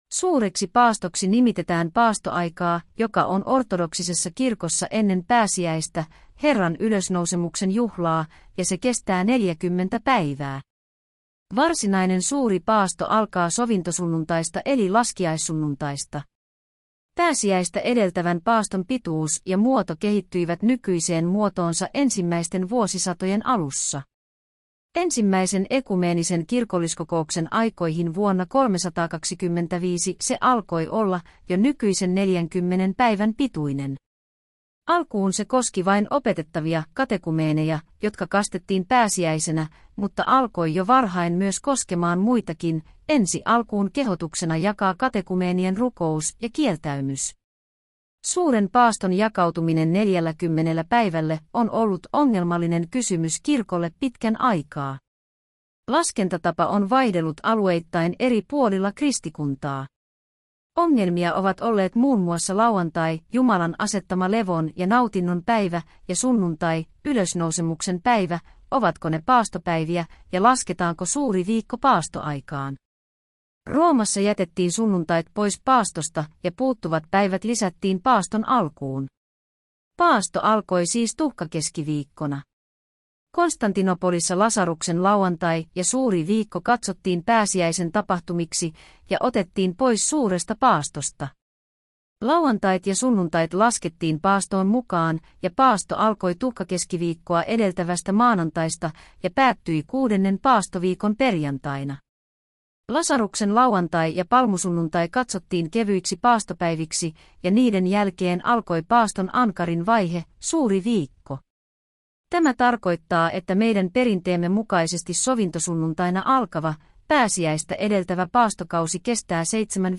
Suuri paasto artikkeli luettuna